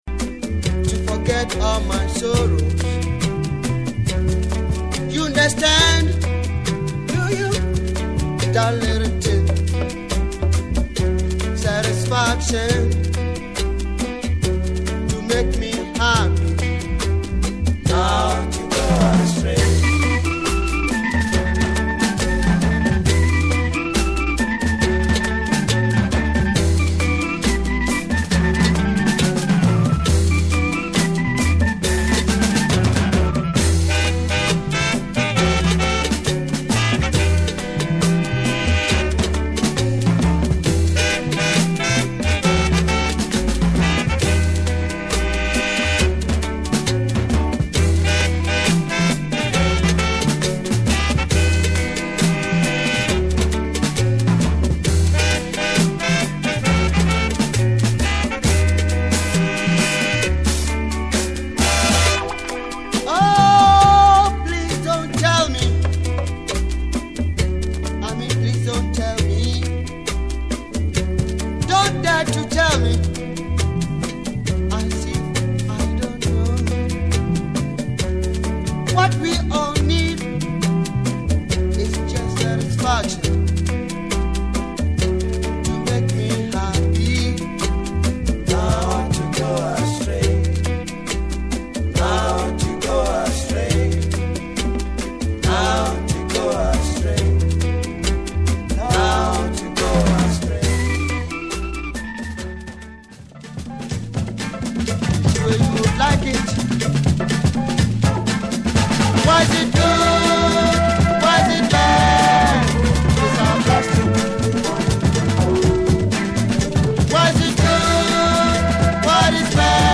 黒くファンキーなAfro Funkアルバム！！